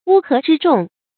wū hé zhī zhòng
乌合之众发音
成语正音 之，不能读作“zī”。